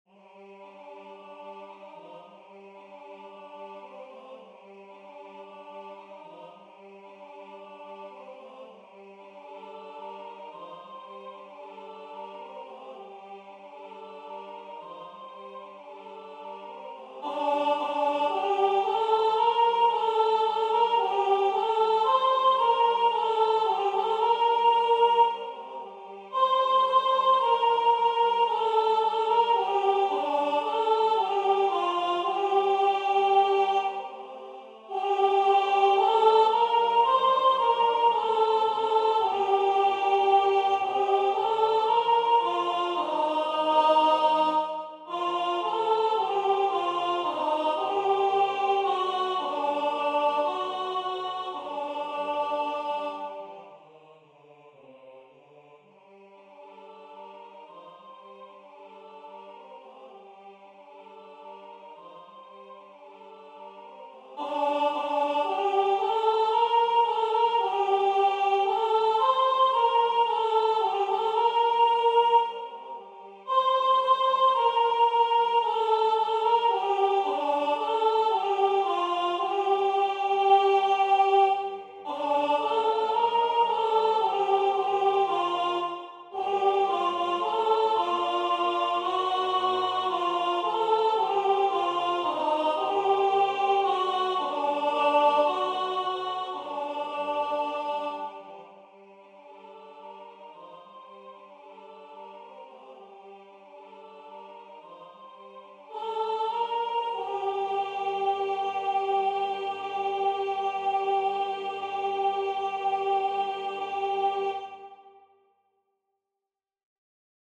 Alto Voix Synth